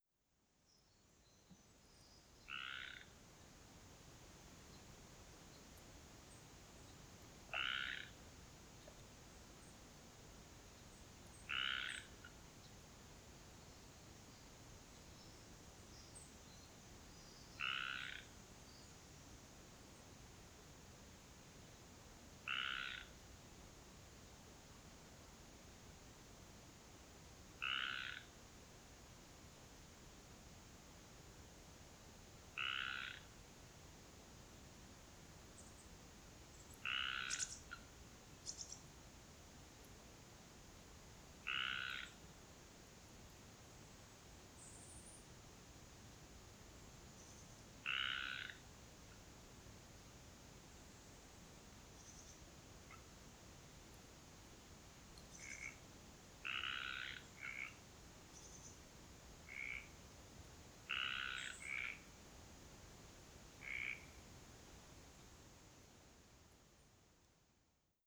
Recordings from the trail through the peaceful second growth forest at Hemer Provincial Park in early spring 2022.
4. Frog Solo (joined by another towards the end) and a Chickadee